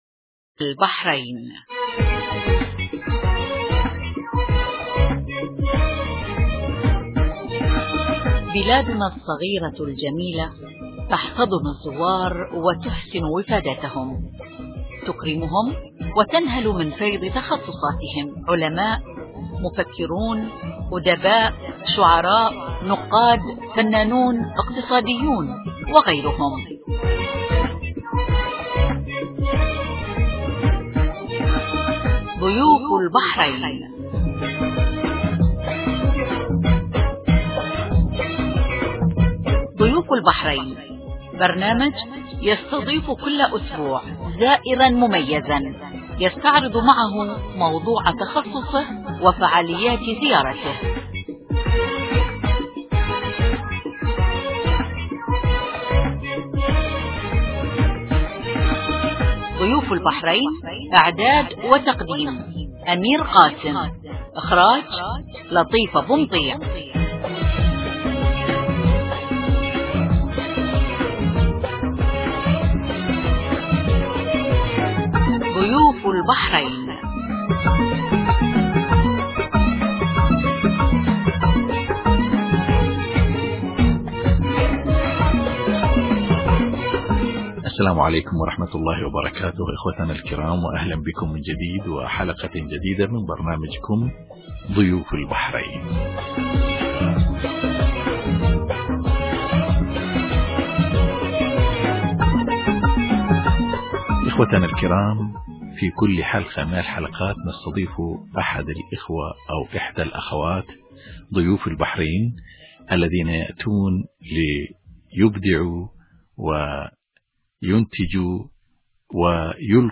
حوارات اذاعية 2009 | الكاتبة والاديبة زينب حفني
﴿ إذاعة – البحرين ﴾